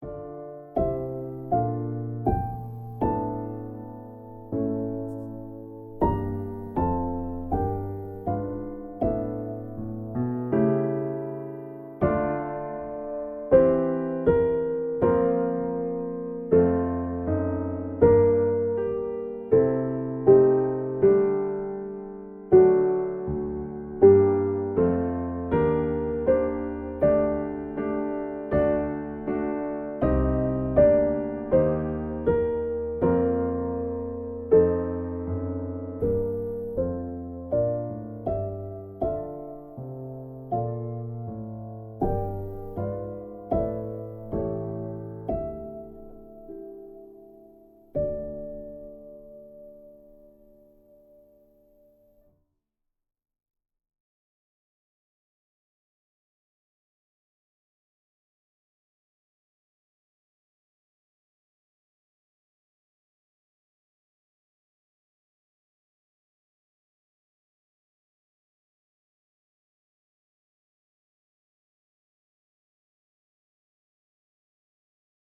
Op.26 No.1 deel 1 Chorale Piano 3-handig december 2000